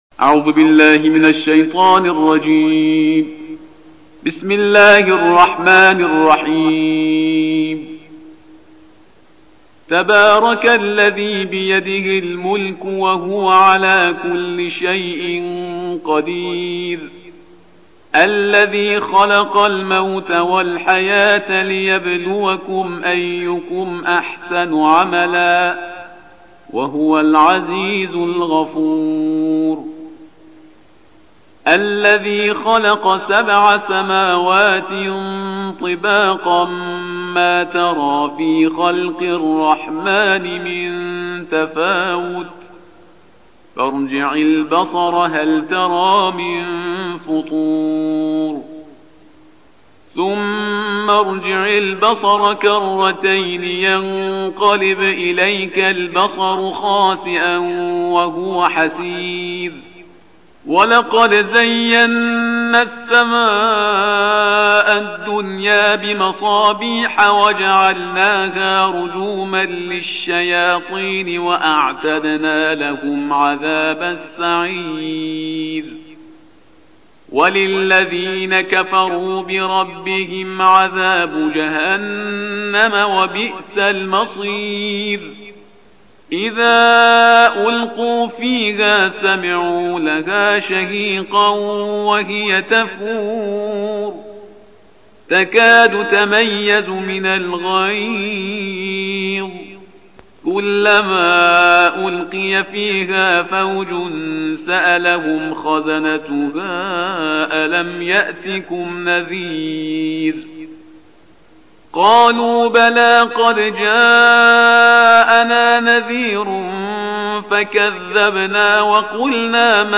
تلاوت ترتیل جزء بیست و نهم کلام وحی با صدای استاد